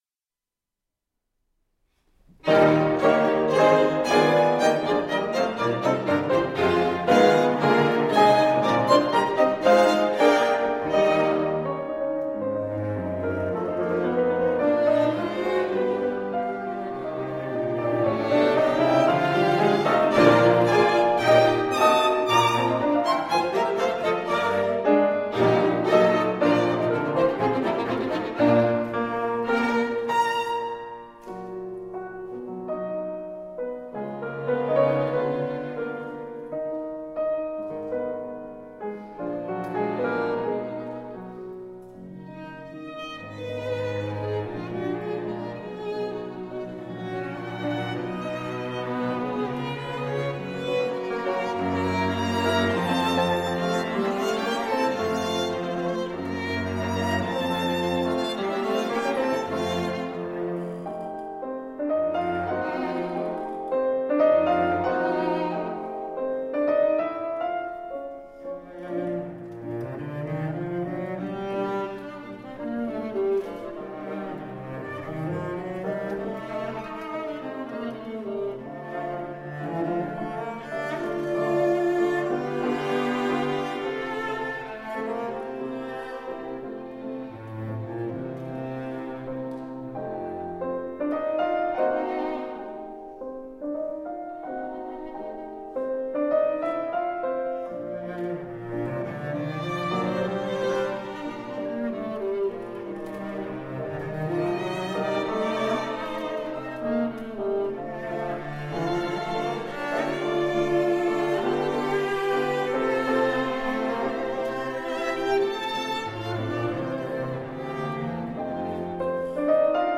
Artist Faculty Concert recordings 2013-07-10 | Green Mountain Chamber Music Festival
violin
viola
cello
piano